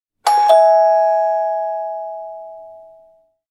Etr Doorbell